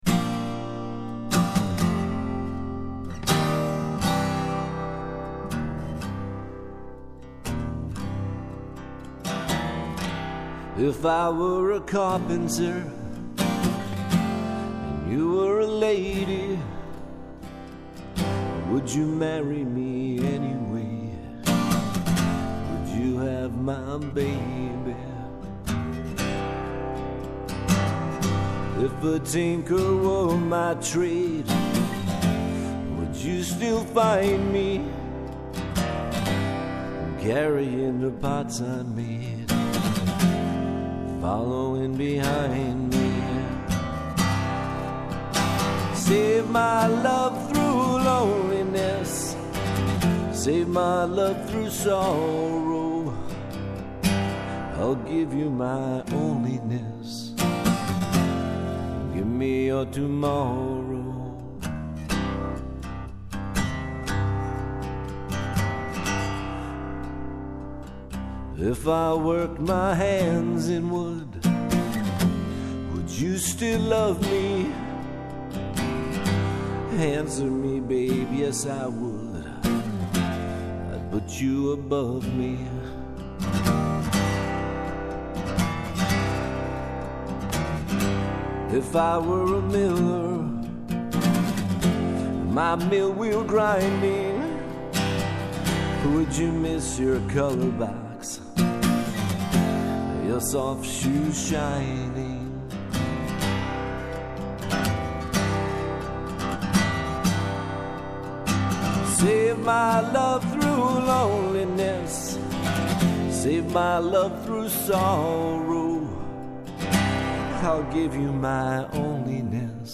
Vocals & Acoustic Guitar, Keys, Background Vocals
Lap Steel